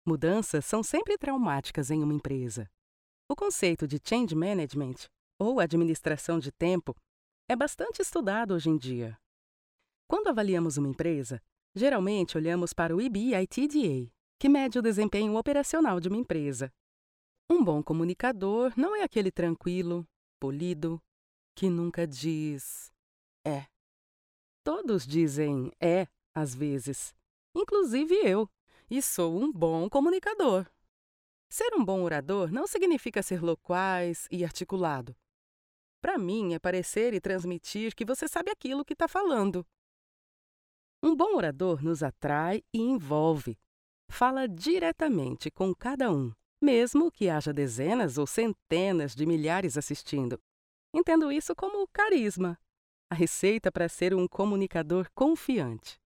Native Brazilian speaker with a professional recording home studio with high quality equipment, makes your recording clear, natural and clean, without noise interference.
His voice is balanced and corporate, with a relatable grace that brings any project to its absolute peak.
Sprechprobe: eLearning (Muttersprache):
I will save you a natural, mature and convincing voice.
E-Learning_7.mp3